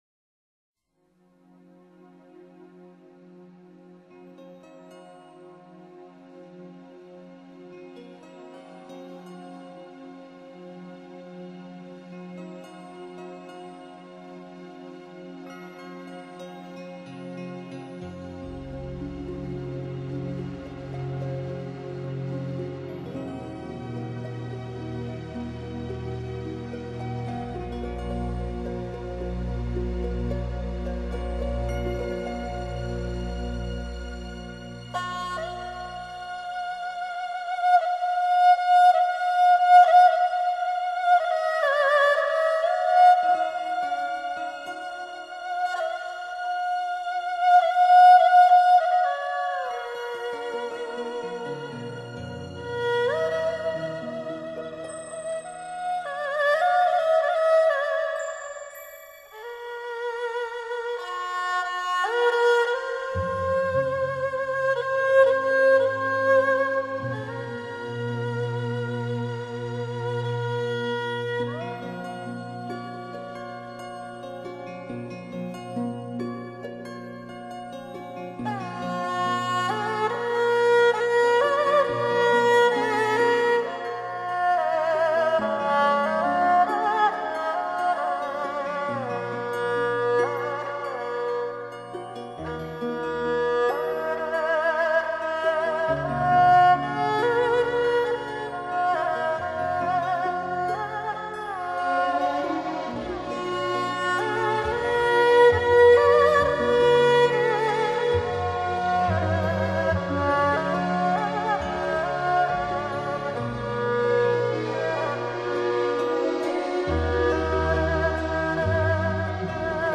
用New Age 等现代音乐手法再现中国民族音乐的精粹，在唱片界已不是什么新鲜的创意。